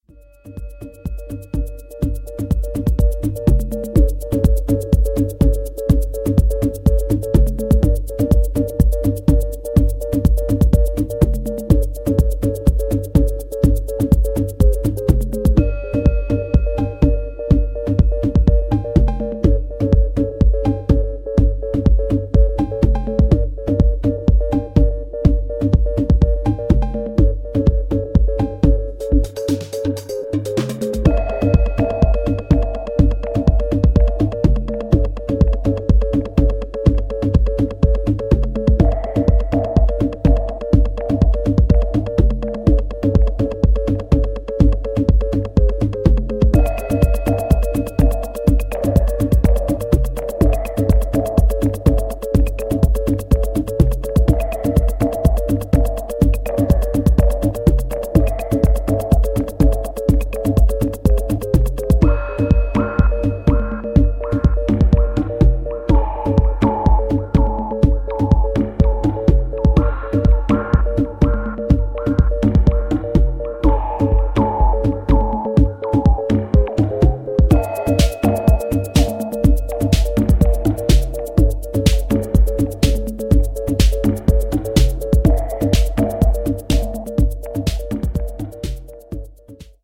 Dark analog jacking trax!